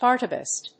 音節har・te・beest 発音記号・読み方
/hάɚṭəbìːst(米国英語), hάːṭəbìːst(英国英語)/